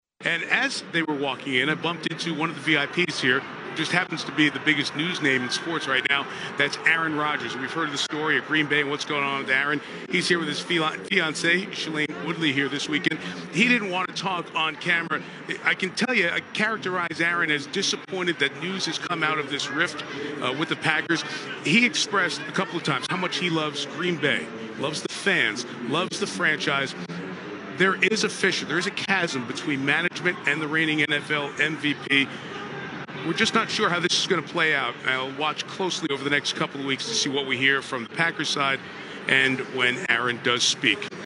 NBC’s Mike Tirico actually tried to interview Rodgers about the bombshell story that broke on the opening day of the weekend NFL draft.
mike-tirico-on-rodgers-at-derby.mp3